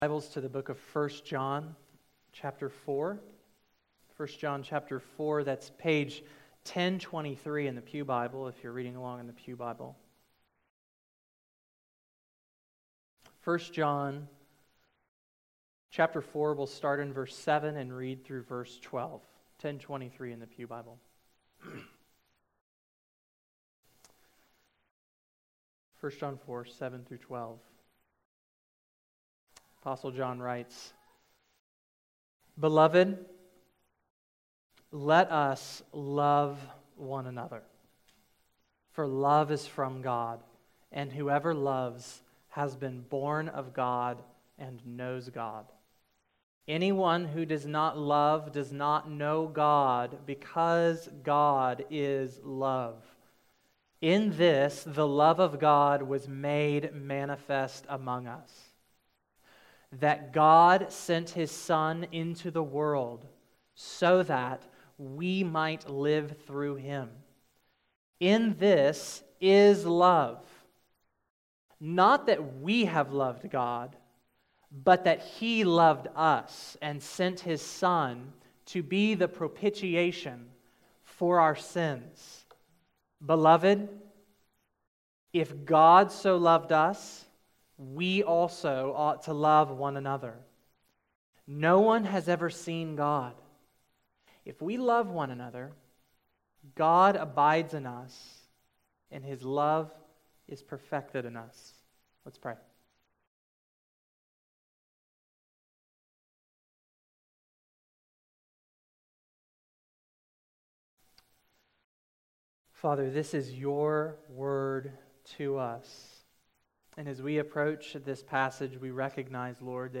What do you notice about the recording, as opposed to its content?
February 5, 2017 Morning Worship | Vine Street Baptist Church